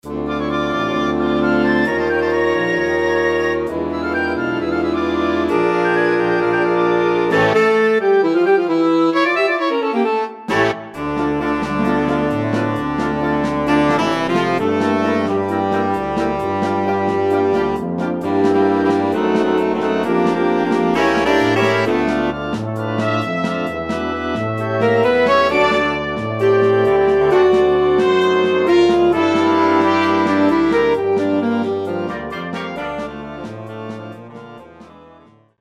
Rozrywkowa